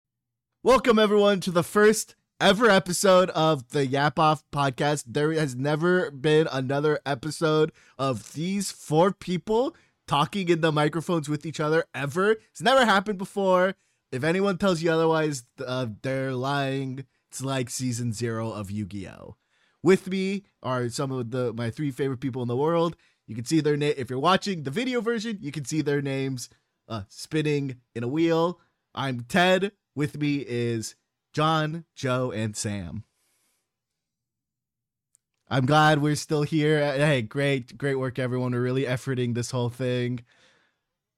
So sit back, relax, and enjoy as four friends yap off!